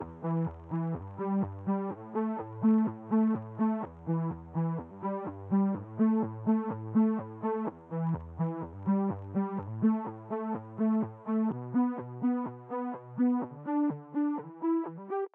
synthe_sourd.wav